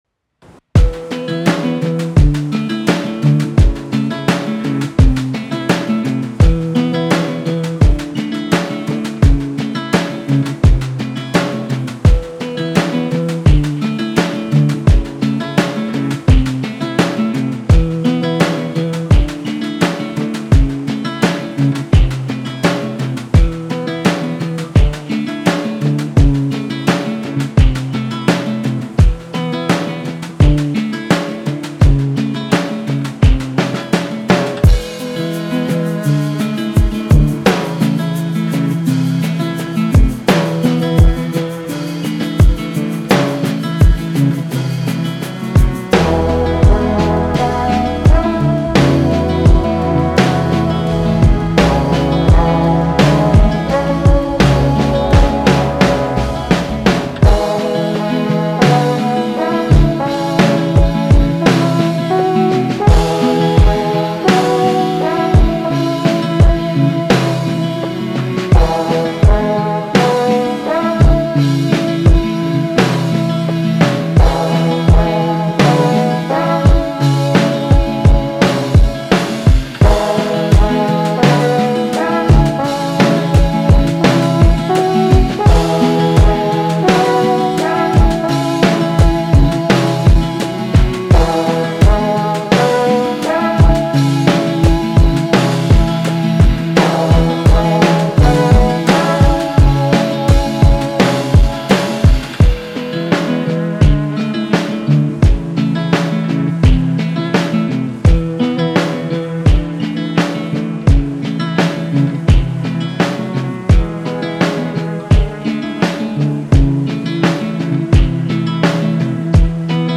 Folk
E Major